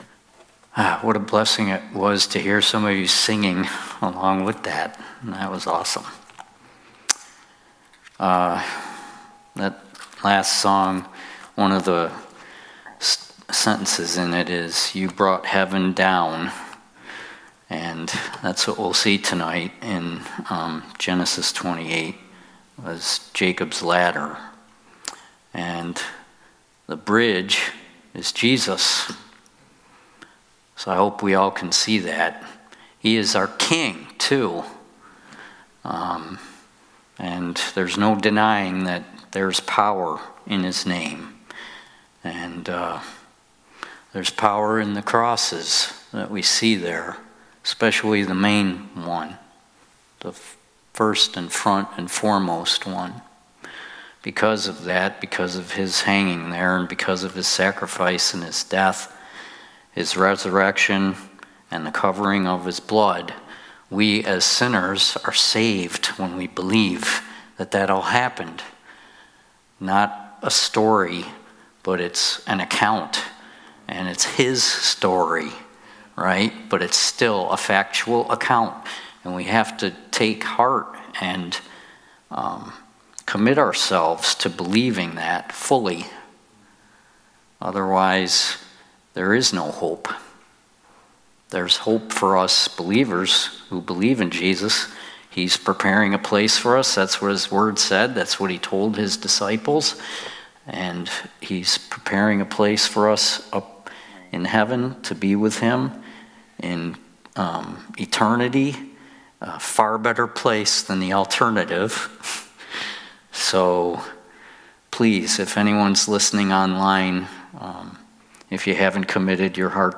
Sunday Night Bible Study